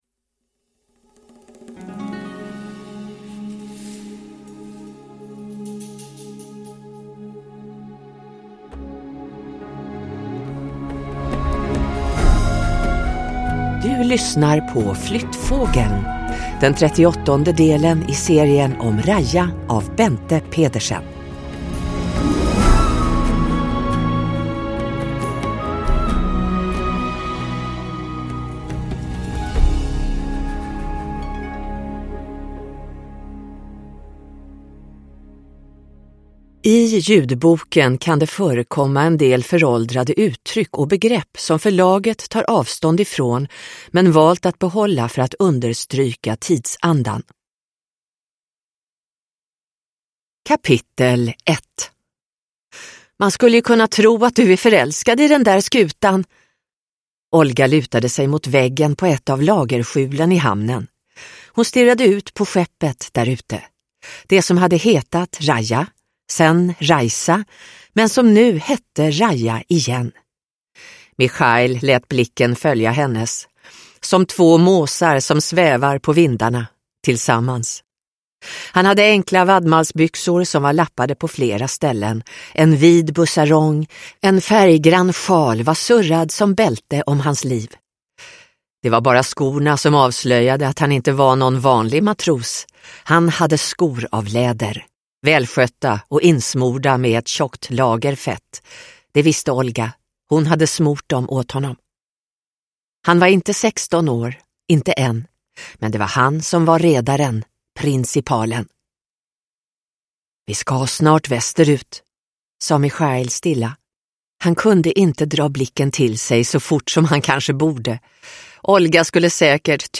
Flyttfågeln – Ljudbok – Laddas ner